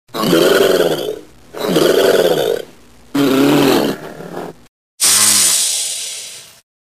1croco.mp3